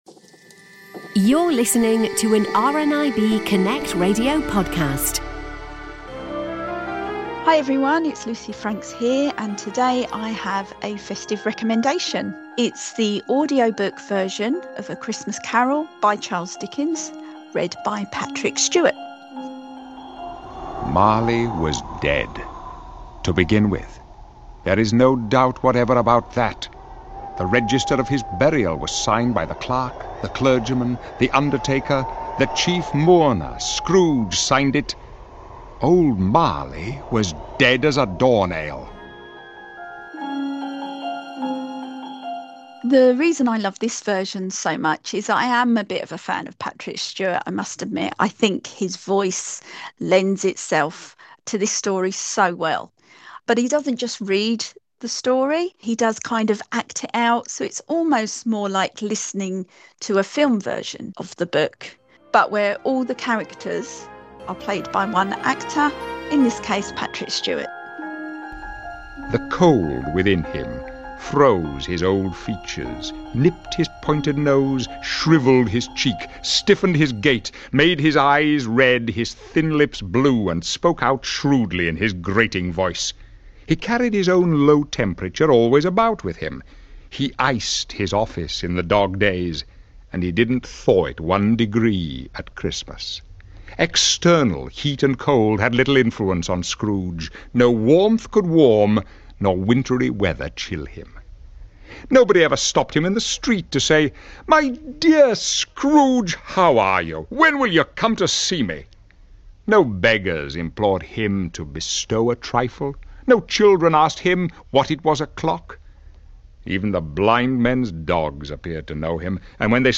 It's a holiday classic read by Sir Patrick Stewart.